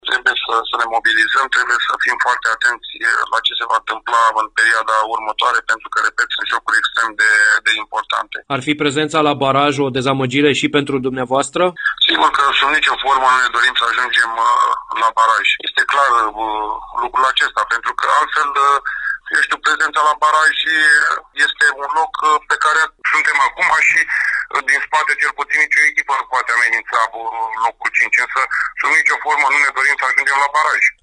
Invitat astăzi, la „Arena Radio”